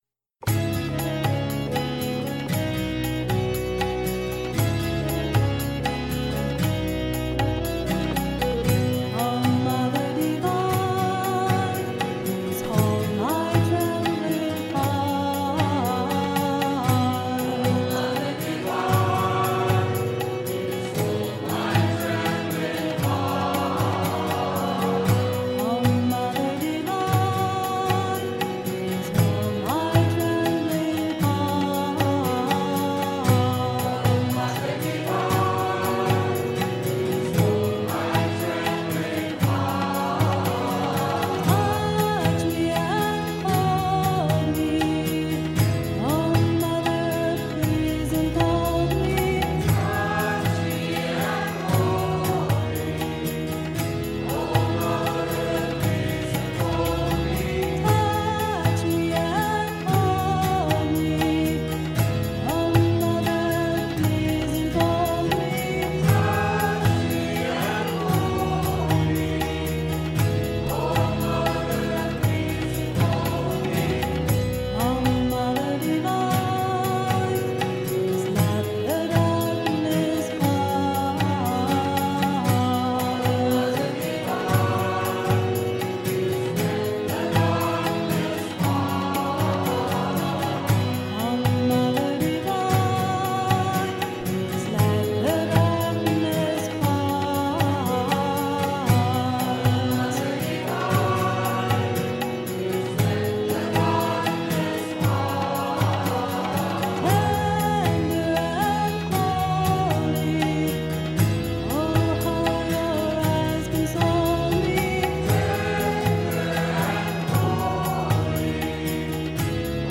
1. Devotional Songs
Major (Shankarabharanam / Bilawal)
8 Beat / Keherwa / Adi
2 Pancham / D
7 Pancham / B